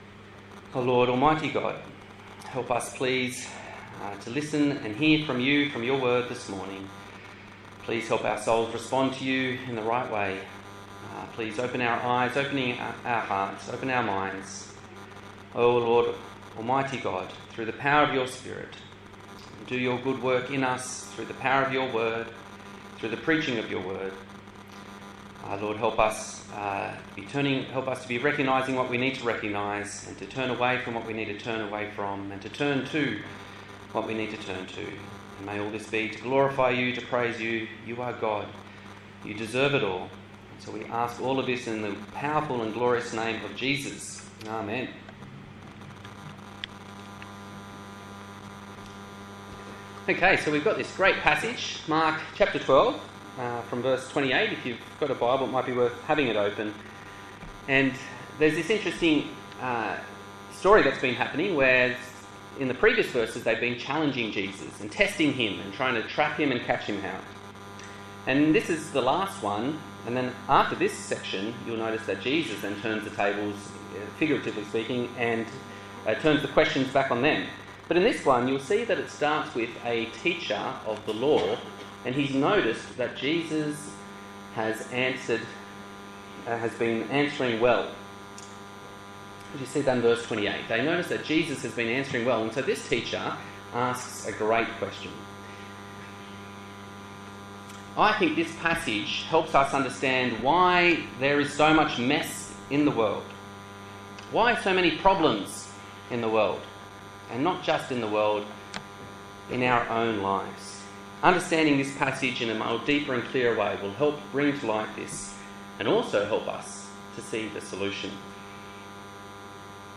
A sermon from the Gospel of Mark
Service Type: Sunday Morning